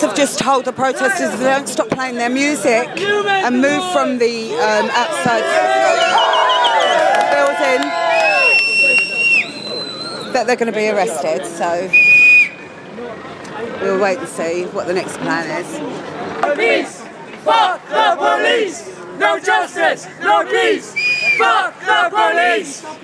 Protesters are threatened if they don't stop playing their music